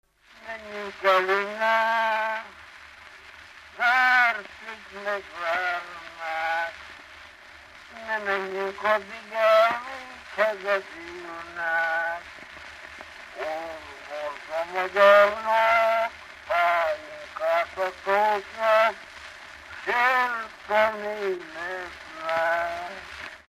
Alföld - Bihar vm. - Nagyszalonta
ének
Gyűjtő: Kodály Zoltán
Stílus: 5. Rákóczi dallamkör és fríg környezete